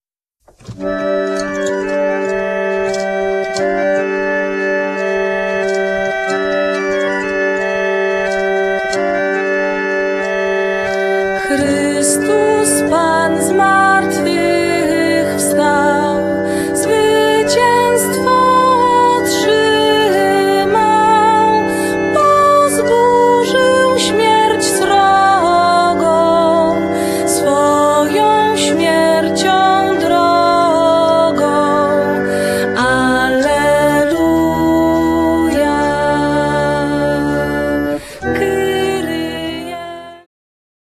śpiew, skrzypce
kontrabas, fisharmonia
altówka, fisharmonia
altówka, lira korbowa